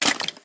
snd_skeletondie.ogg